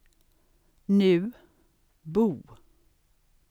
KAPITEL 5 - TALEORGANERNE 5.2: svensk [nʉ̟β: buβ:] 5.2: italiensk [ˈfɔʎ:a]